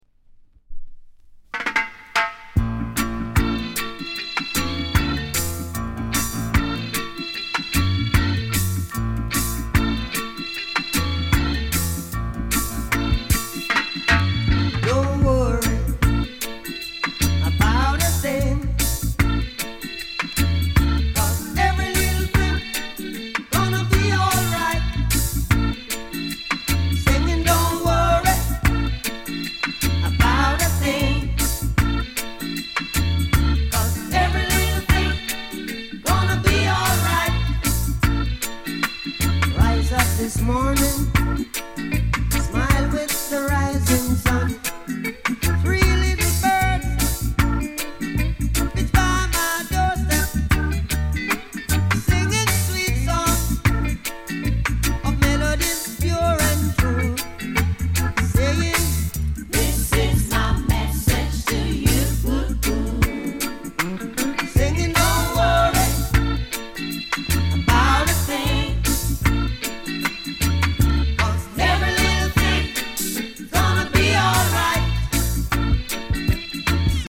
類別 雷鬼